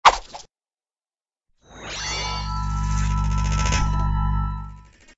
TL_small_magnet.ogg